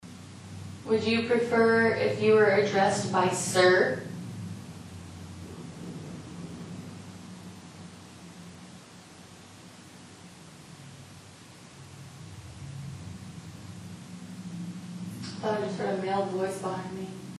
EVPs
Male voice
You can hear a muffled man's voice in this clip after one of our investigators asks if they want to be addressed as Sir. This was in the Military room.